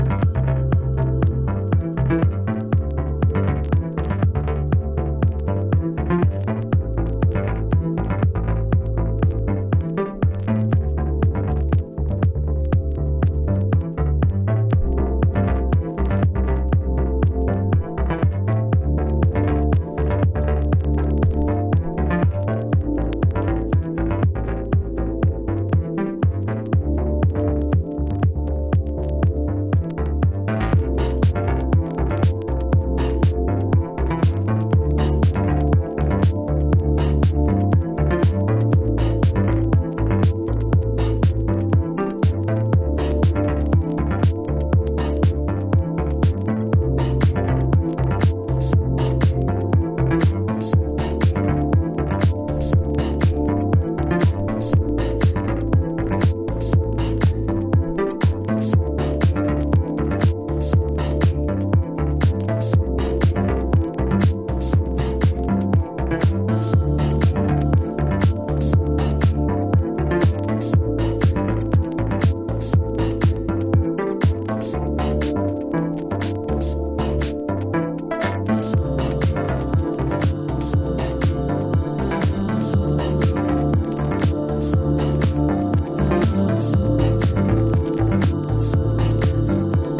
メランコリックなシンセとフランス語Vo.が醸すアンニュイなムードが◎なディープハウス！